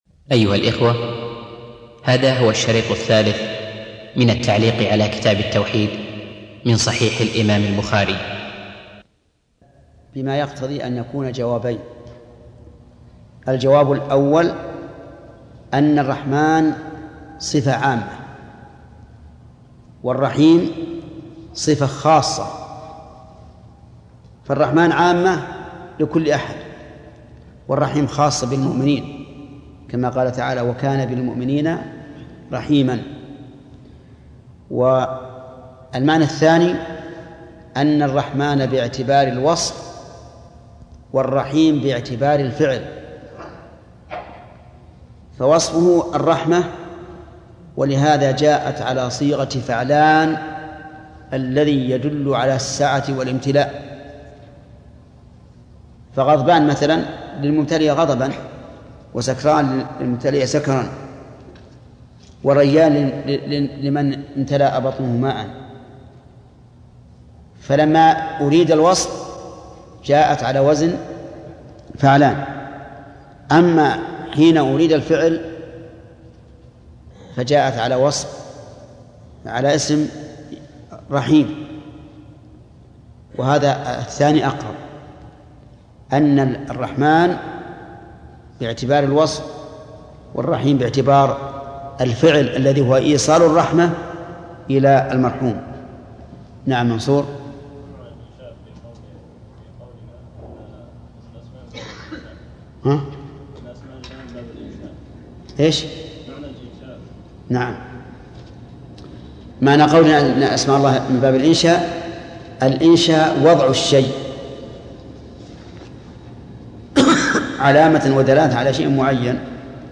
الدرس الثالث -شرح كتاب التوحيد من صحيح البخاري - فضيلة الشيخ محمد بن صالح العثيمين رحمه الله